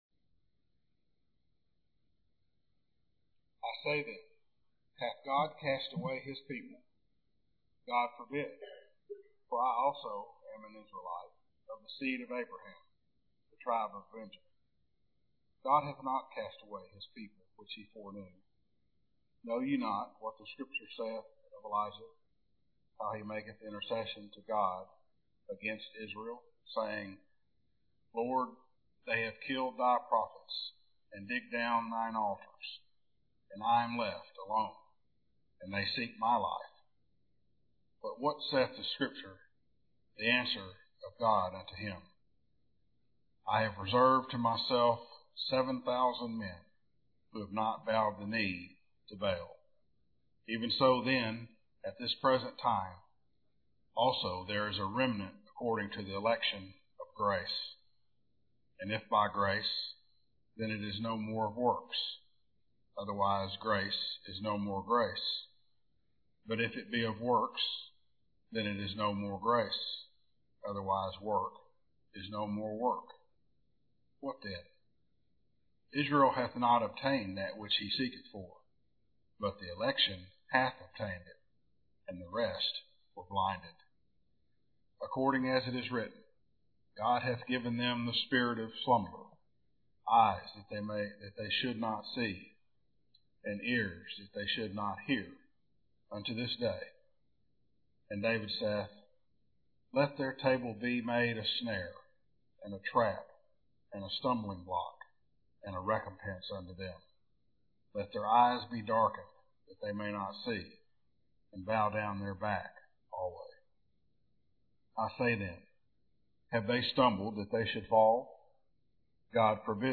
Explore our sermon library below to play, download, and share messages from McKinney Bible Church.